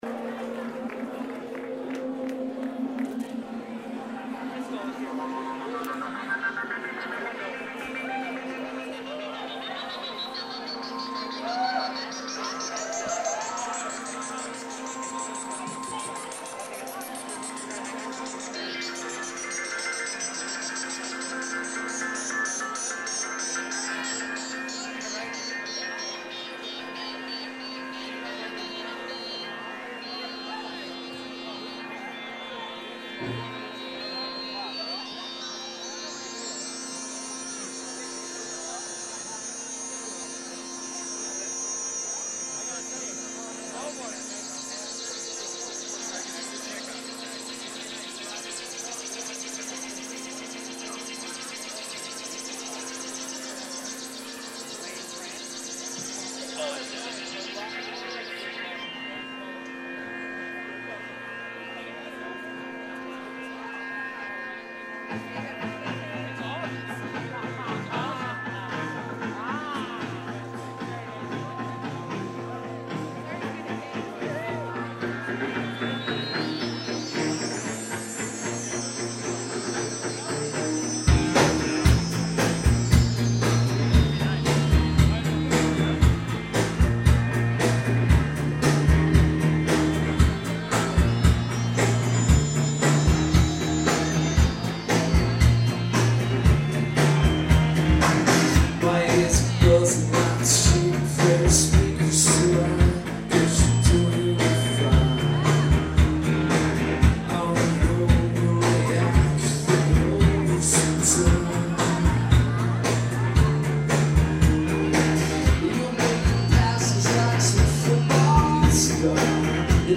irving plaza nyc feb 28 2003